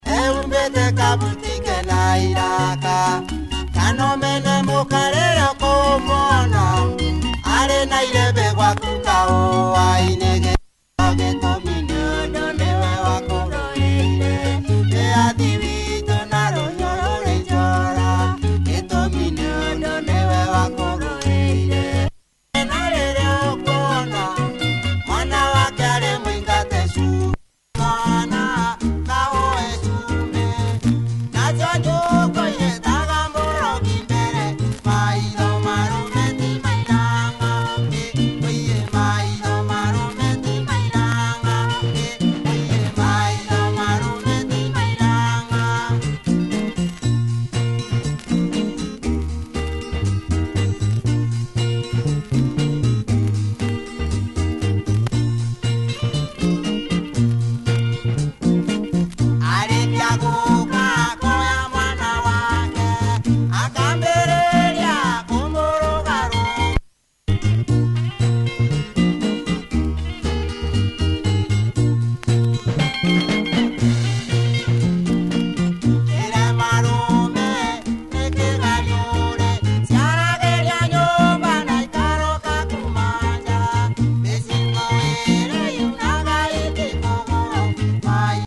benga
nice bumping guitar.